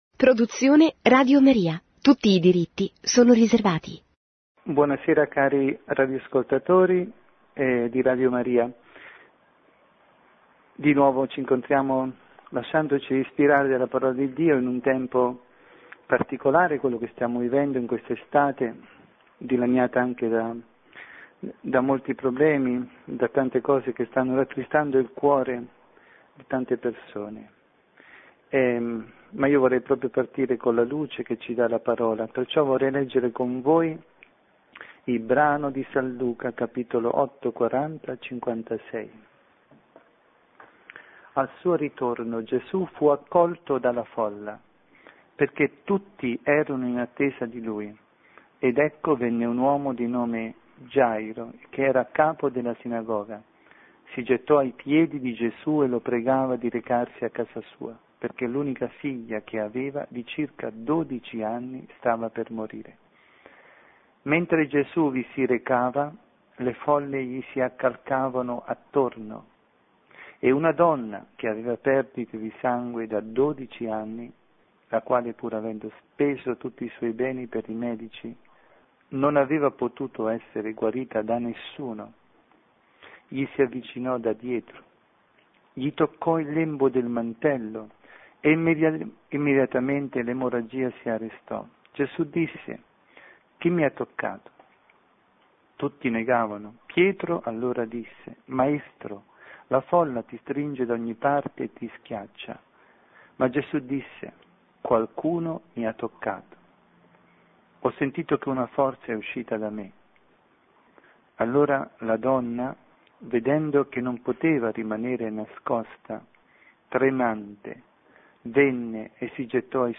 Lectio
dalla Parrocchia S. Rita Milano – Vangelo del Rito Ambrosiano di Domenica 26 marzo 2017 – Giovanni 9, 1-38b.